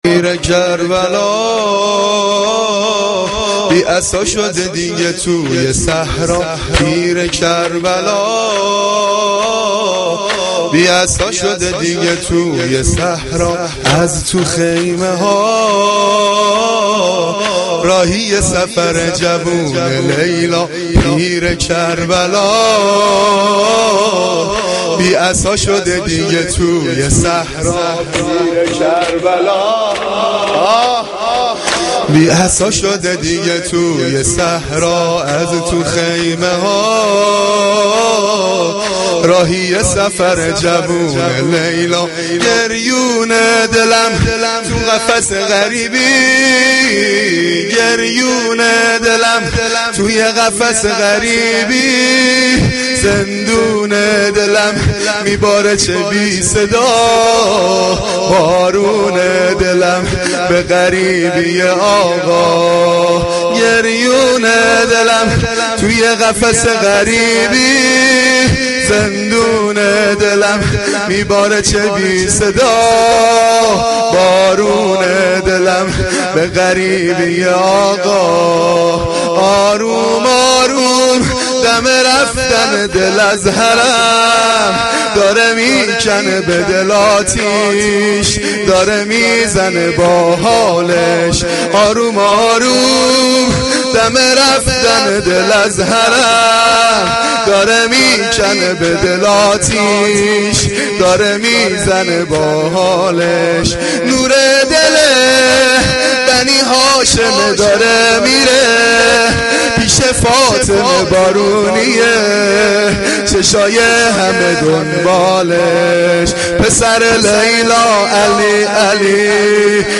مداحی
Shab-8-Moharam-4.mp3